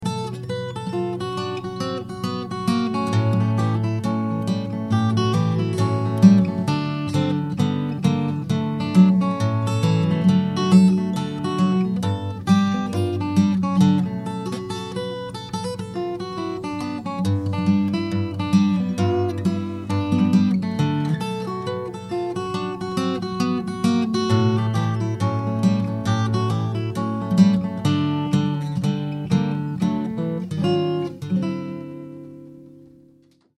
Ashington Folk Club - Singers, Musicians & Poets 01 June 2006
In blues mood